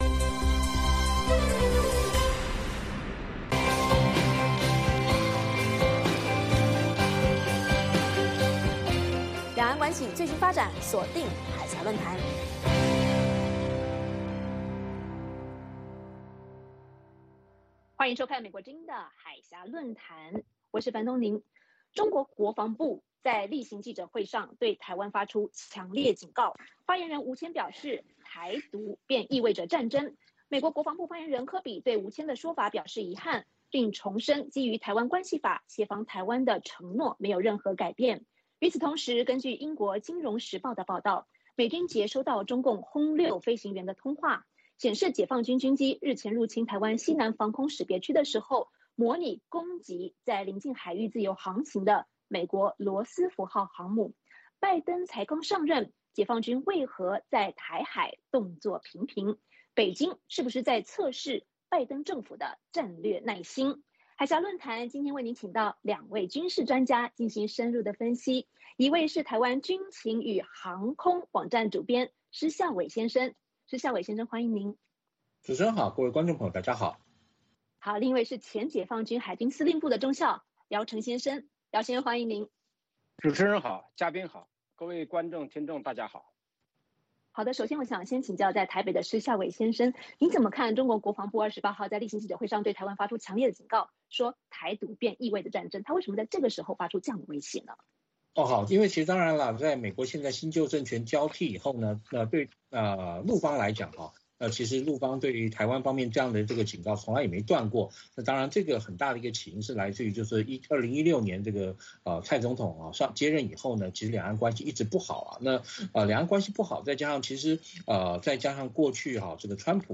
美国之音中文广播于北京时间每周日晚上9点播出《海峡论谈》节目(电视、广播同步播出)。《海峡论谈》节目邀请华盛顿和台北专家学者现场讨论政治、经济等各种两岸最新热门话题。